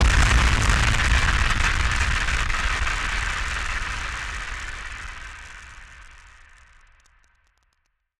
BF_DrumBombB-08.wav